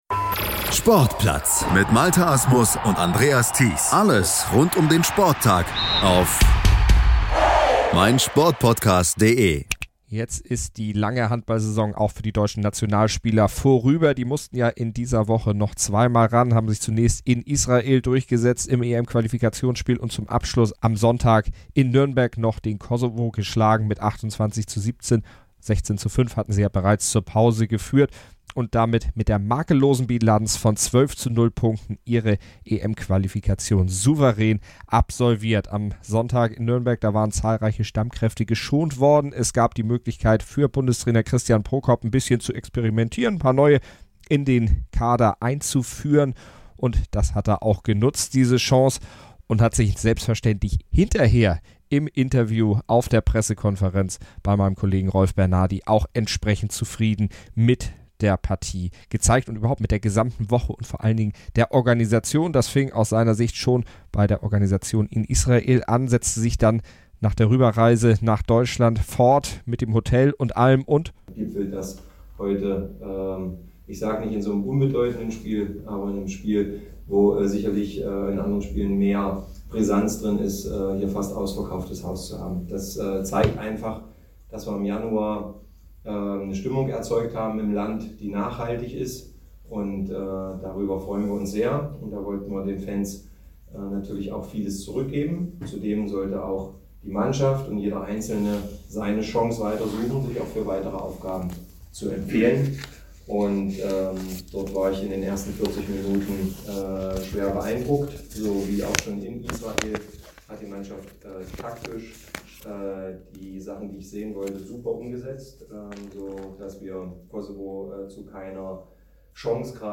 Außerdem sprach unser Reporter noch mit weiteren DHB-Spielern, die ihre Visitenkarte beim ohne viele Stammkräfte agierenden Team abgeben durften.